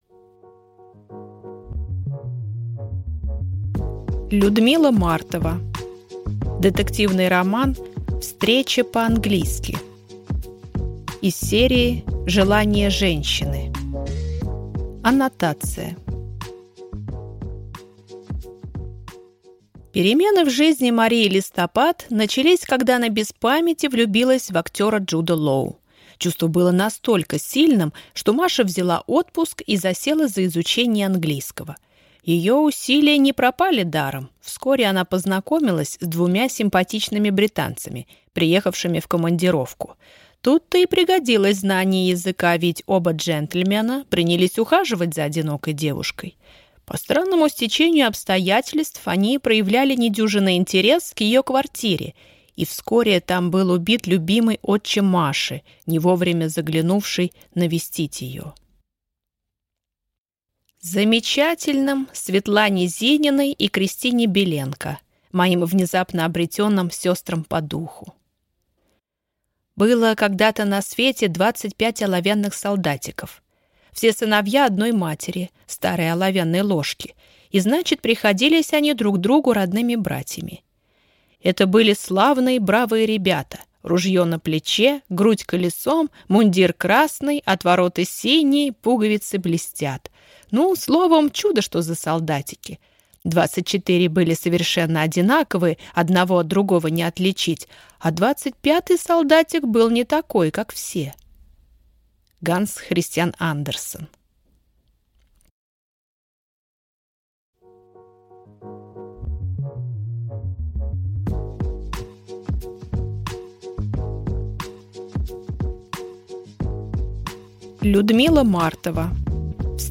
Аудиокнига Встреча по-английски | Библиотека аудиокниг